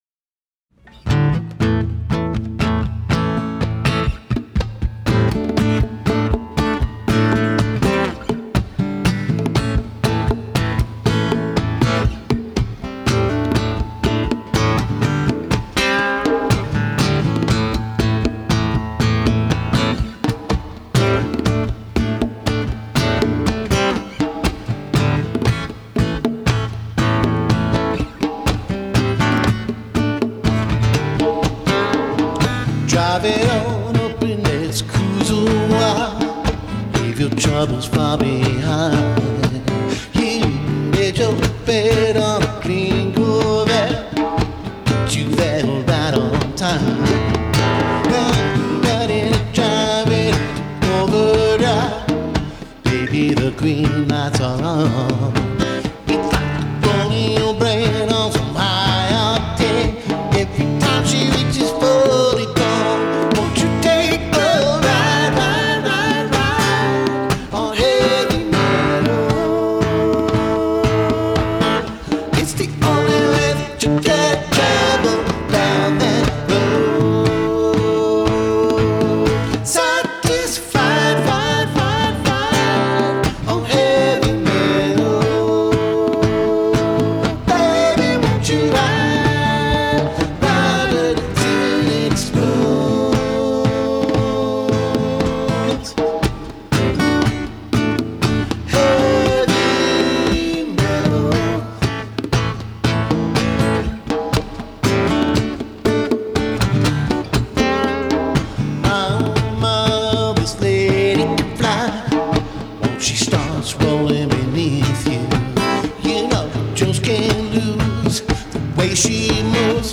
This is transposed version to Am from Em.
Acoustic